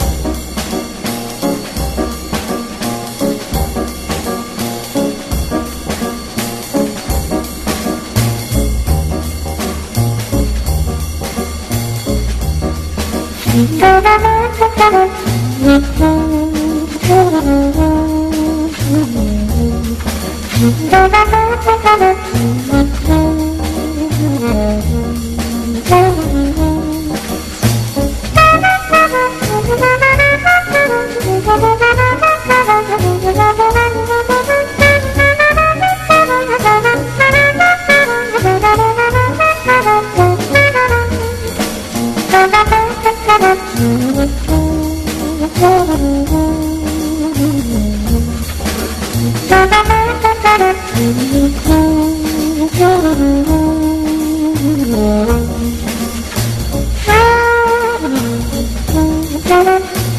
JAZZ / MAIN STREAM / VIBE
メランコリックなメロディが心地良い
スリリング＆アヴァンギャルドな